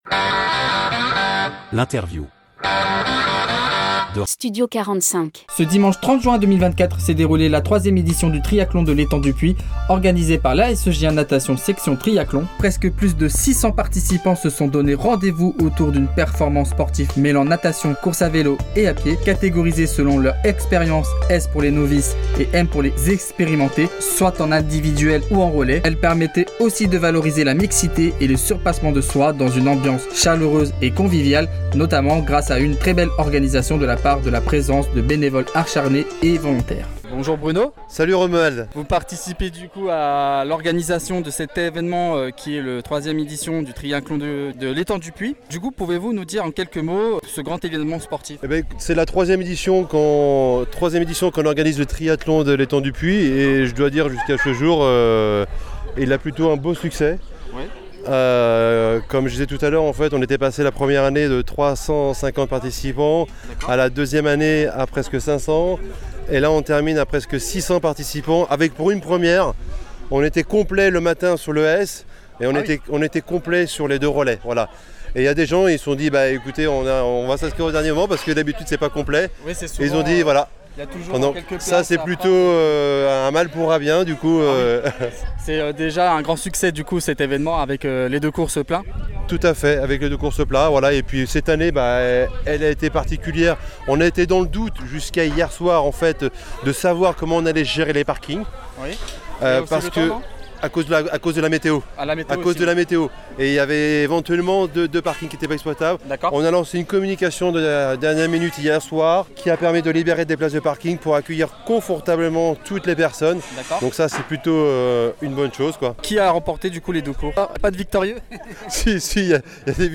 Interview - Triathlon de l'étang du puits